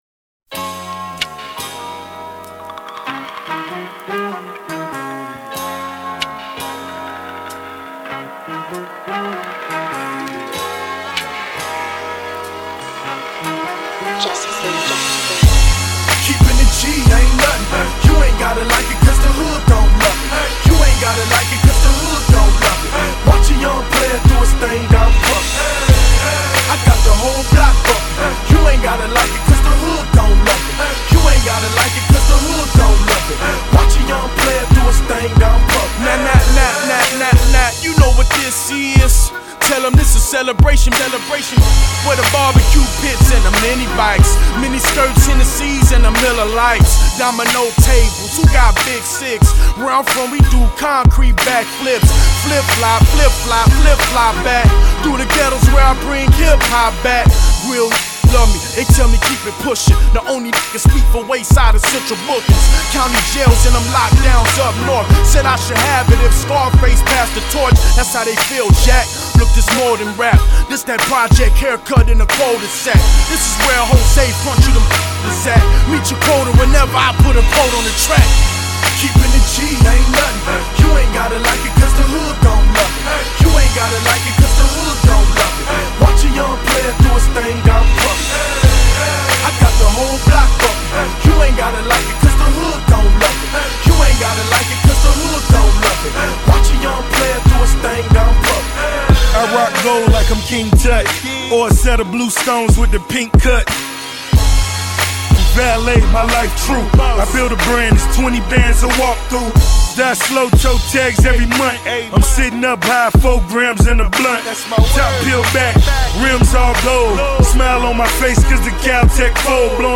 Hip Hop, Music
remix